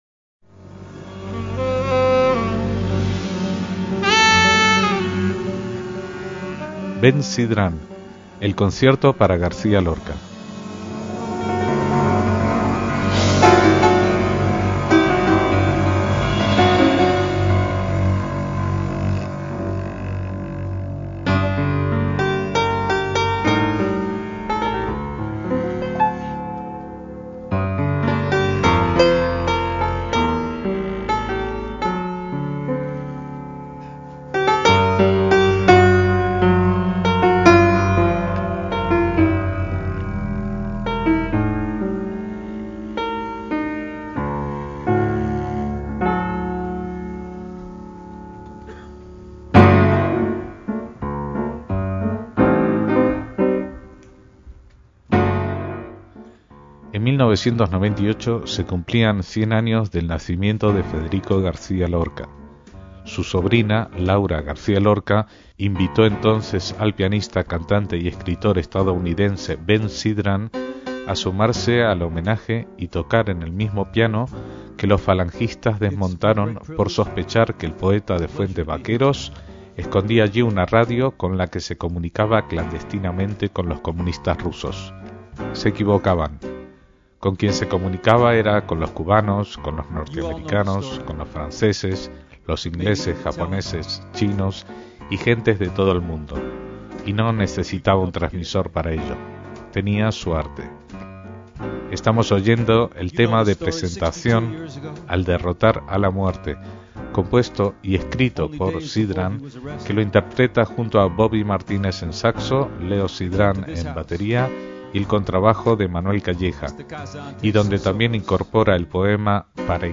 saxo
contrabajo